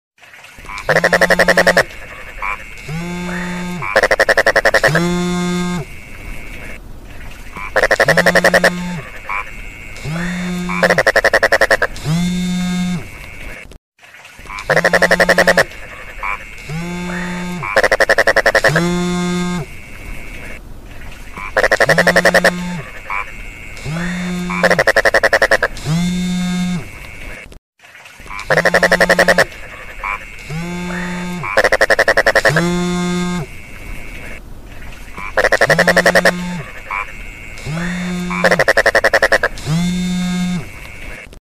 Genre: Nada dering binatang